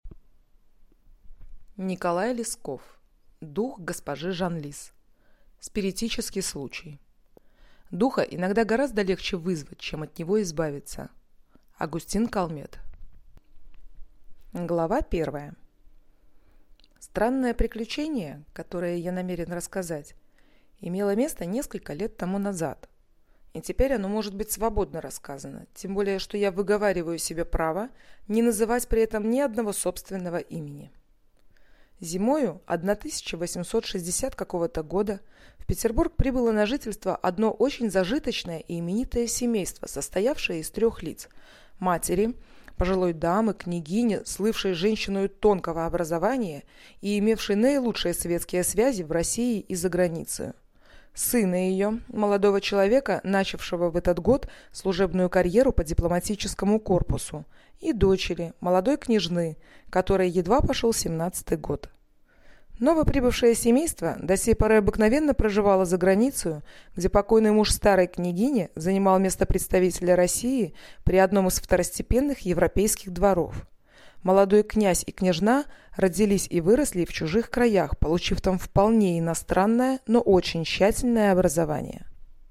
Аудиокнига Дух госпожи Жанлис | Библиотека аудиокниг